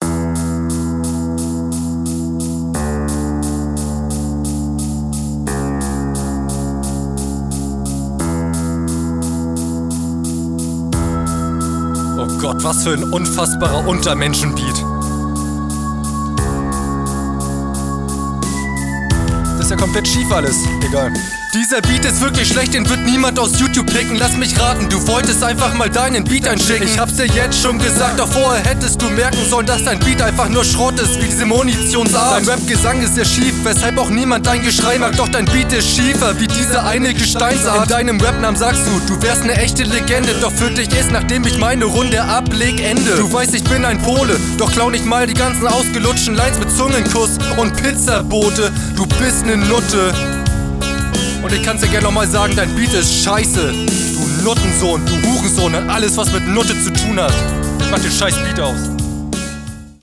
Flow: Du flowst auch sehr solide auf dem Beat.